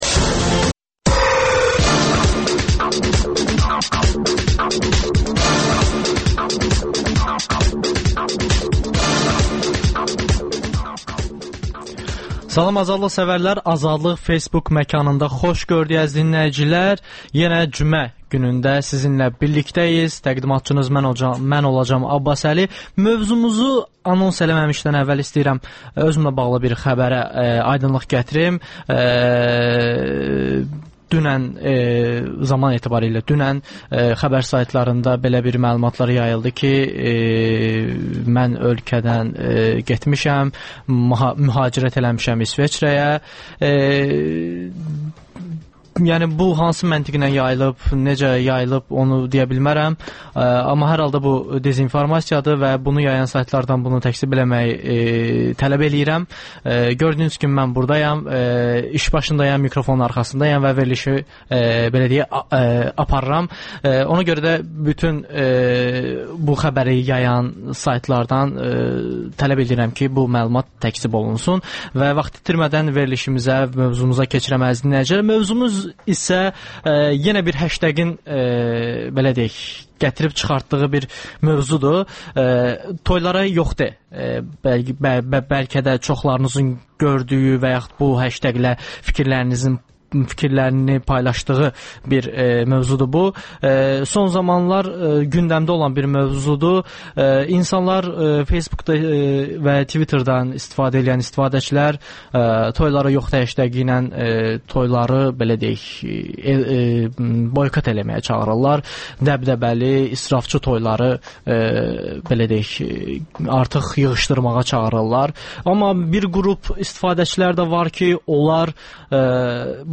Azadlıq Facebook Məkanında. Sosial media məkanında baş verən ən son yeniliklərdən bəhs edən radio şou.